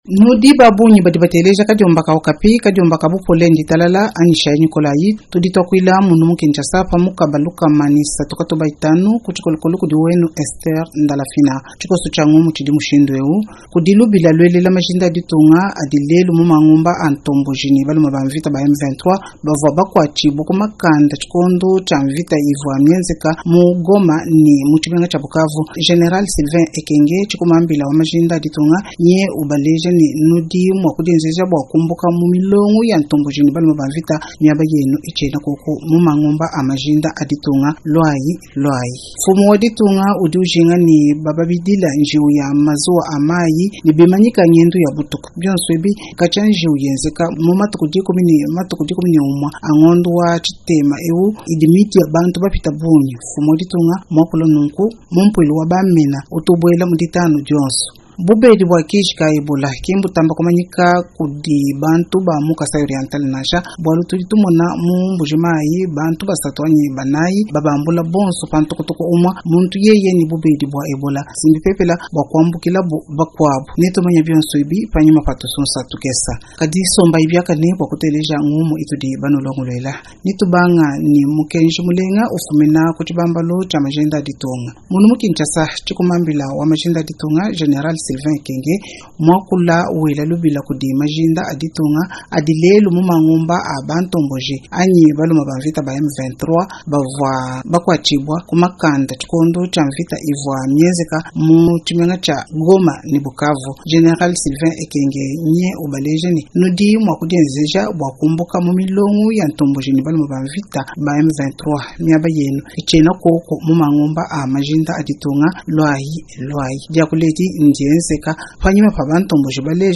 Journal Tshiluba de lundi Matin le 22 Septembre 2025